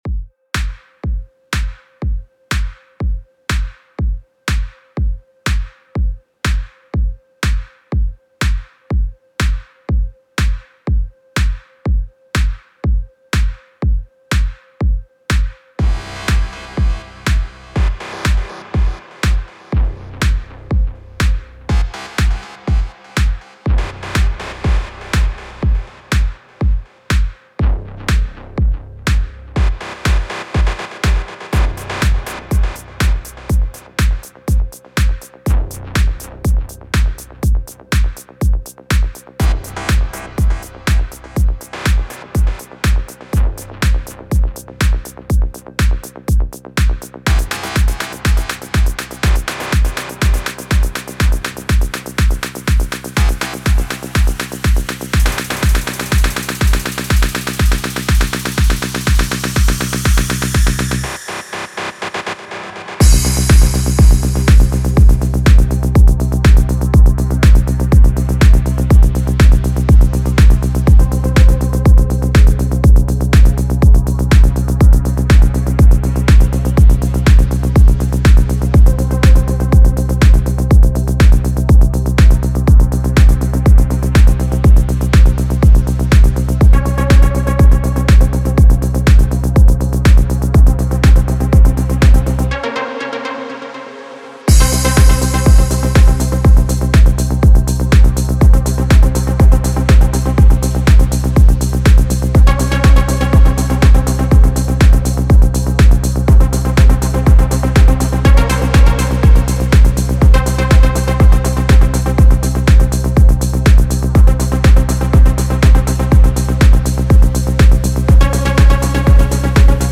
Melodic Techno Progressive House Techno
Melodic Techno / Techno / Progressive Techno